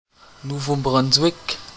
1. ^ French: Nouveau-Brunswick, pronounced [nuvo bʁœ̃swik], locally [nuvo bʁɔnzwɪk]